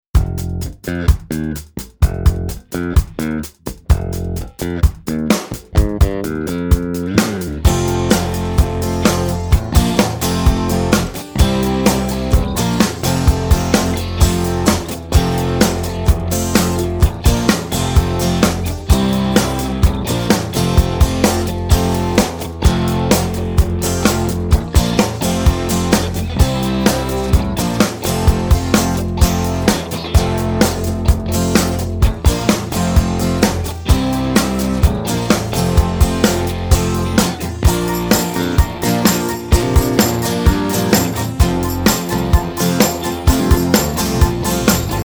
Listen to the Instrumental version of this song.